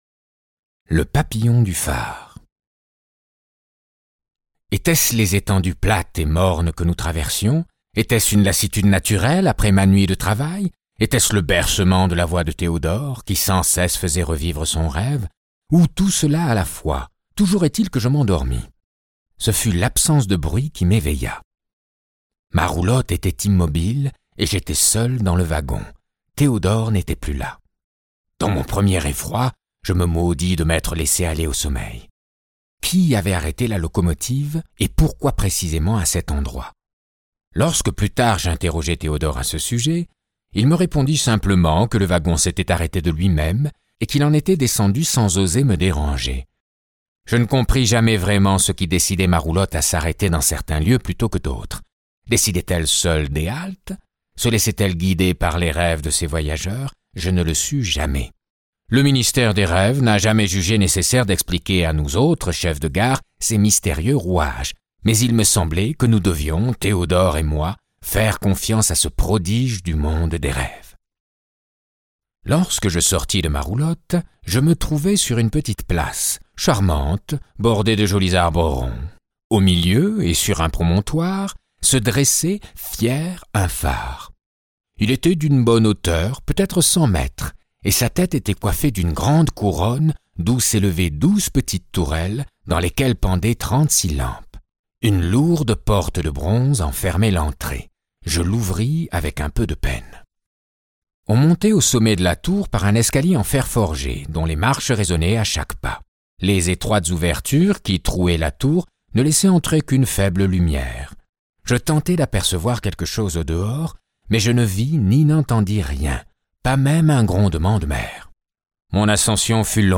Version : Intégrale •1h 15min Le monde des Rêves est peuplé de créatures et de lieux étranges, régis par d'étonnantes lois. On y accède par un train interdit aux Éveillés.